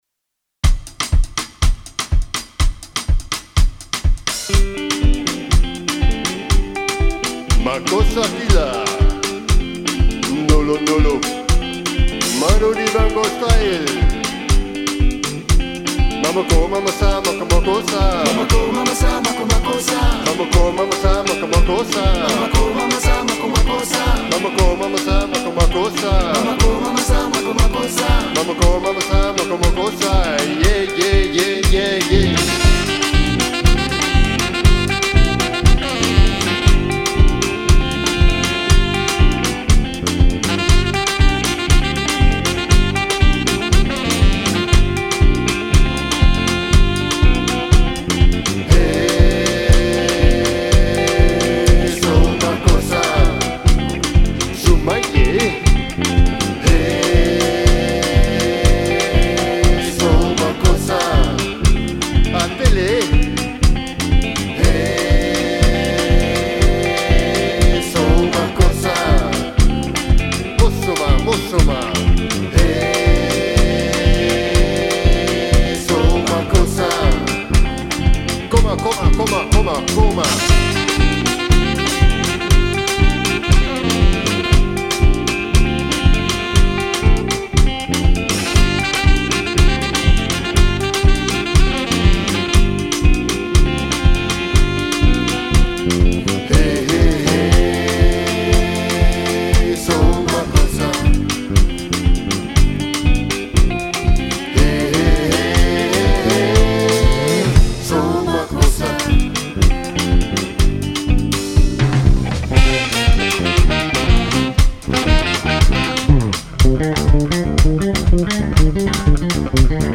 Soukous